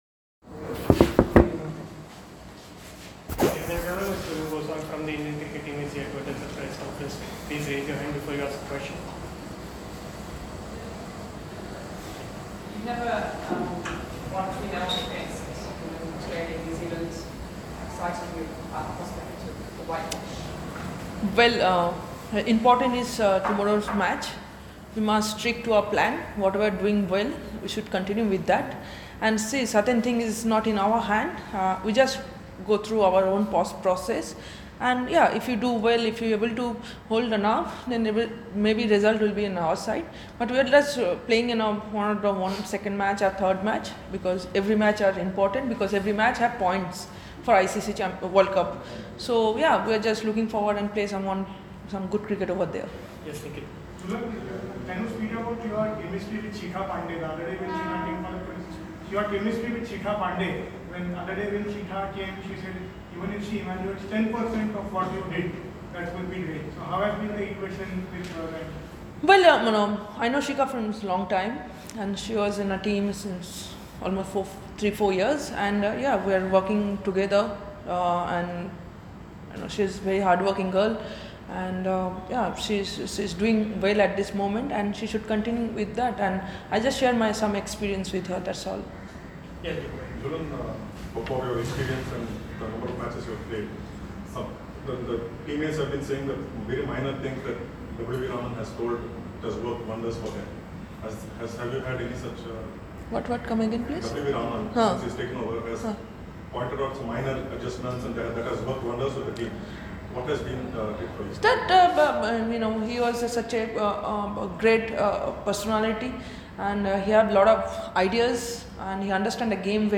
Jhulan Goswami spoke to the media in Mumbai ahead of the third Paytm ODI against England Women.